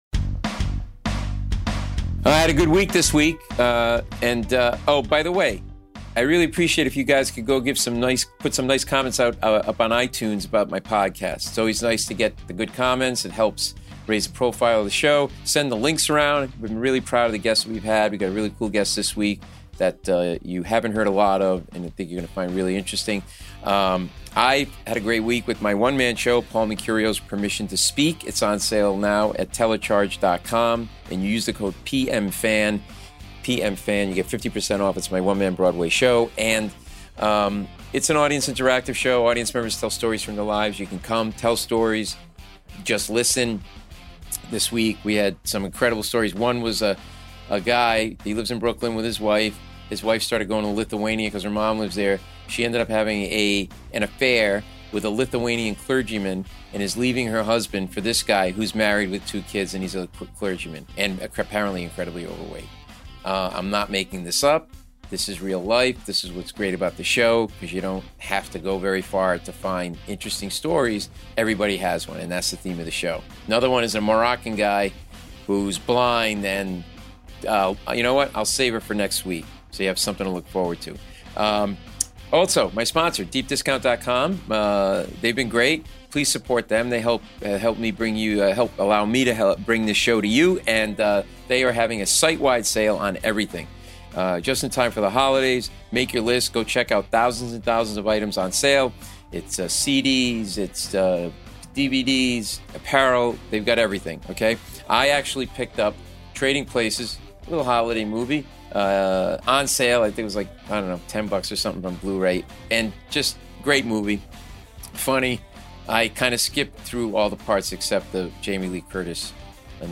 Very relevant to today, this interview will open your eyes to a world you don't hear alot about but is important to our national security.